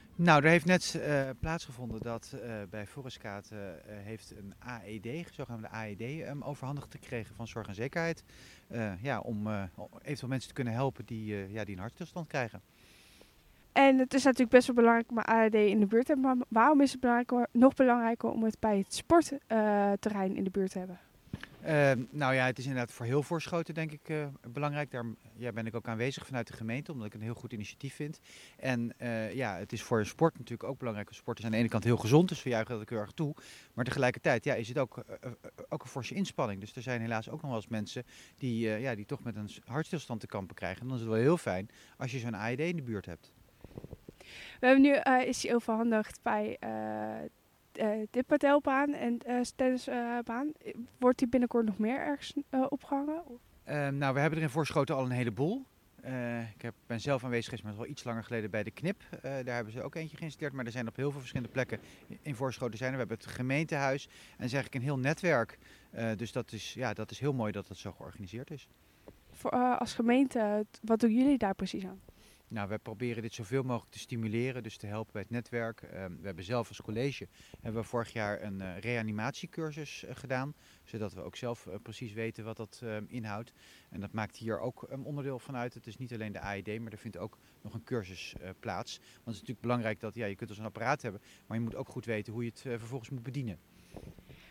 Wethouder Hubert Schokker is namens de gemeente Voorschoten aanwezig, om het netwerk van AED’s te ‘stimuleren’. De wethouder vertelt er zelf meer over.
Interview